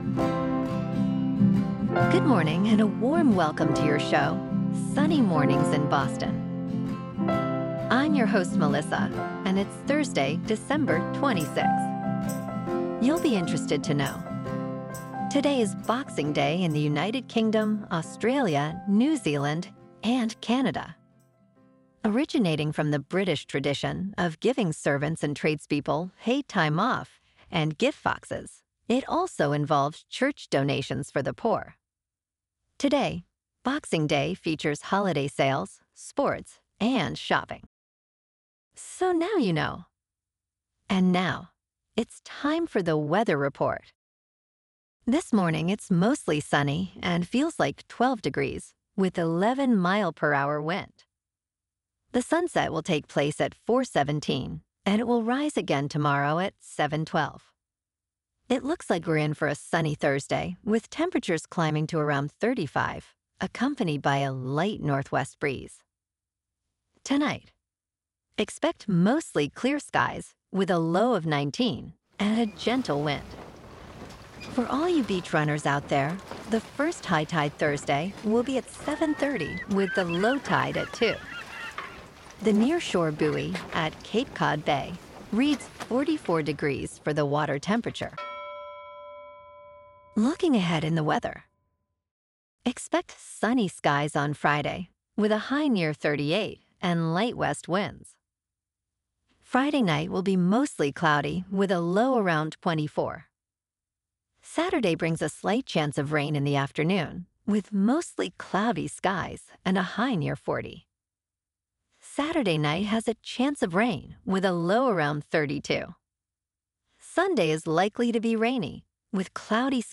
Your "Hyper-Local" 12 Minute Daily Newscast with: